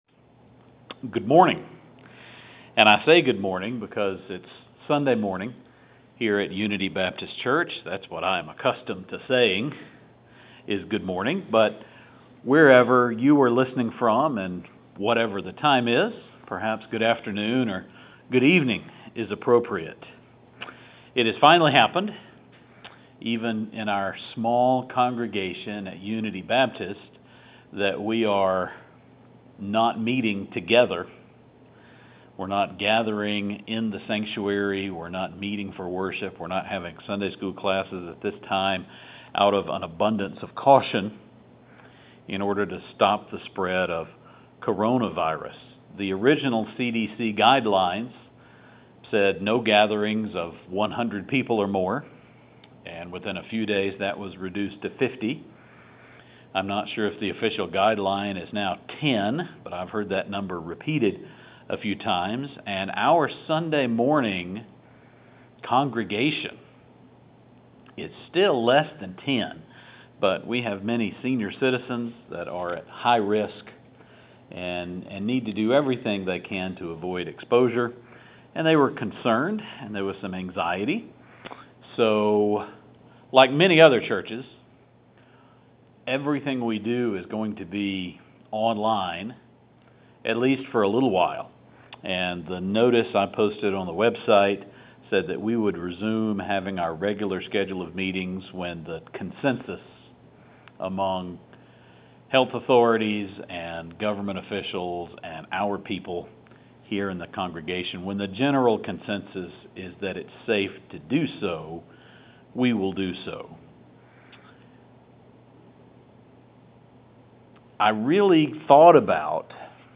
I preached this sermon to an empty house and was successful in making not only this digital recording WAV file but also making an old fashioned cassette tape that our seniors without internet access can listen to.